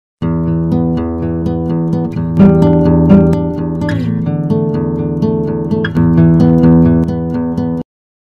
• DROPOUT o CLIP ( Rumore di distorsione o saturazione del segnale audio ), ( a.18 ).